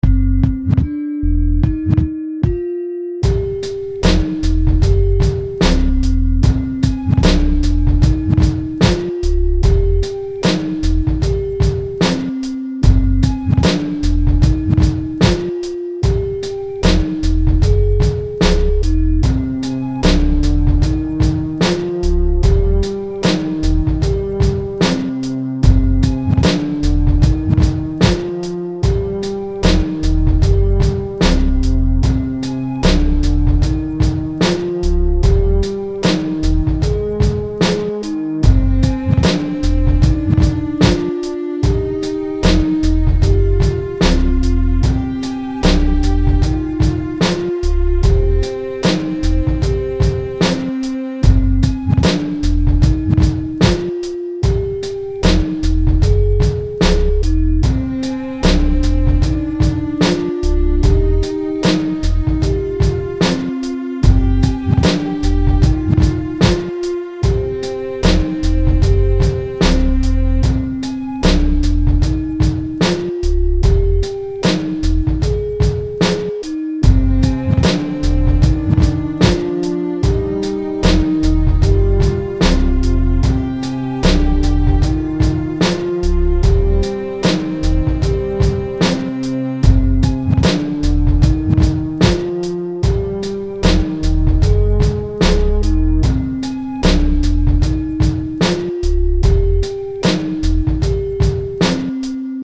grimey